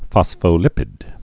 (fŏsfō-lĭpĭd)